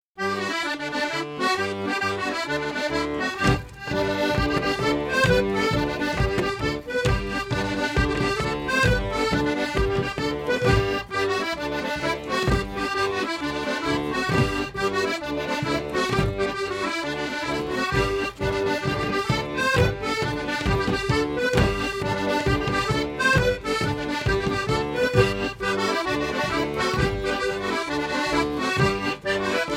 Couplets à danser
branle : courante, maraîchine
Pièce musicale éditée